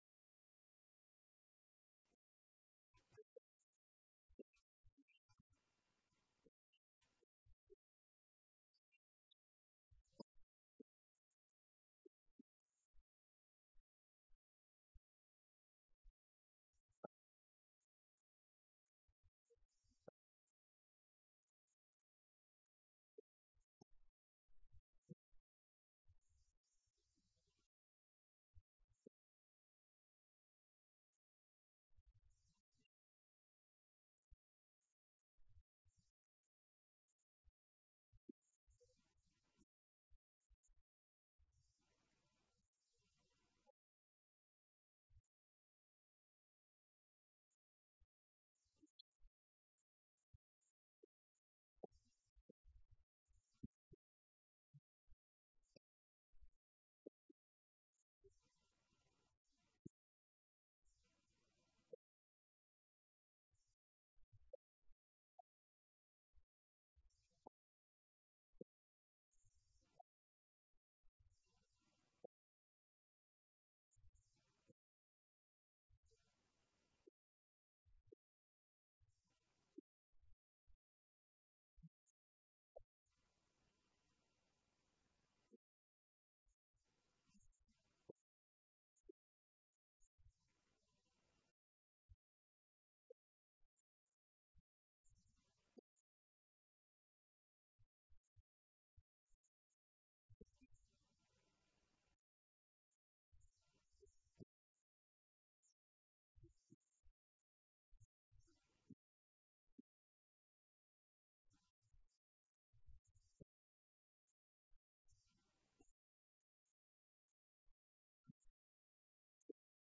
Event: 2019 Men's Development Conference
If you would like to order audio or video copies of this lecture, please contact our office and reference asset: 2019YMDC22